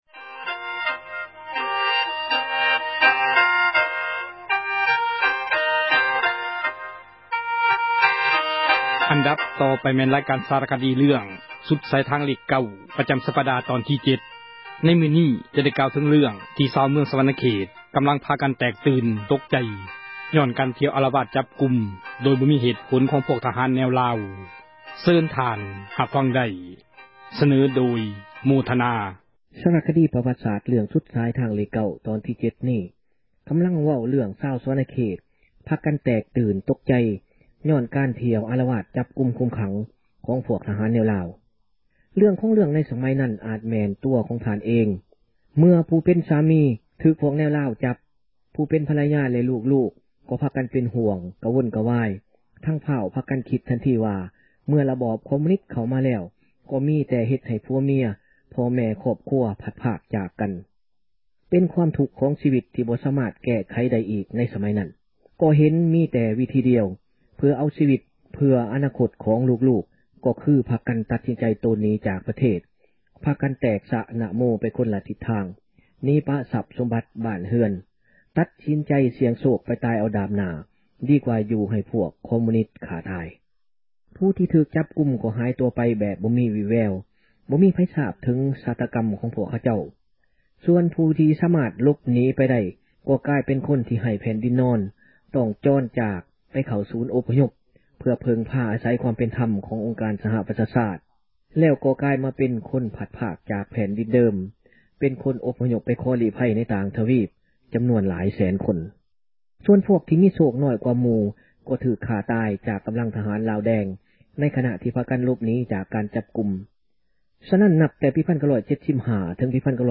ສາຣະຄະດີເຣື້ອງ ສຸດສາຍທາງເລກ 9 ປະຈຳສັປດານີ້ ຈະໄດ້ເລົ່າເຖິງເຣື້ອງ ທີ່ຊາວເມືອງ ສວັນນະເຂດ ໄດ້ພາກັນແຕກຕື່ນ ຍ້ອນການອອກ ອາຣະວາດ ຈັບກຸມຊາວບ້ານ ແບບໄຮ້ເຫດຜົນ ແລະປາສຈາກ ຄຳອະທິບາຍ ຂອງທະຫານພວກ ແນວລາວ.